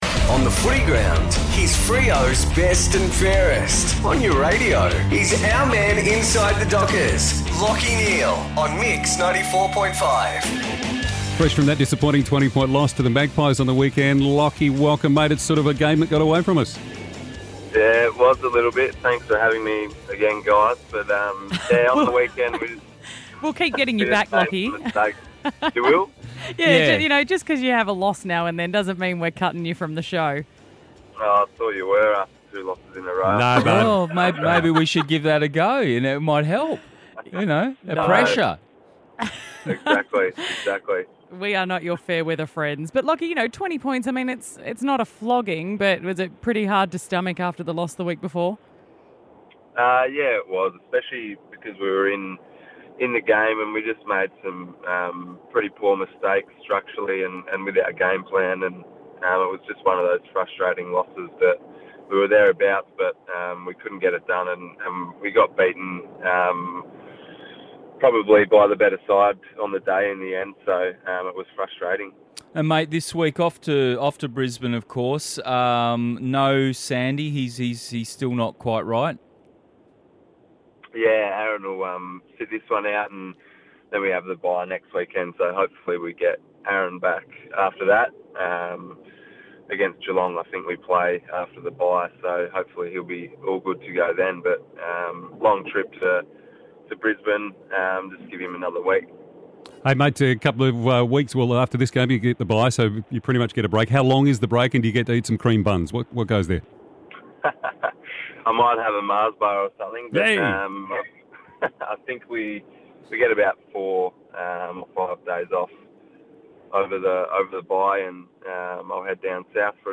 Lachie Neale chats to the team at Mix 94.5 ahead of Freo's clash against the Lions.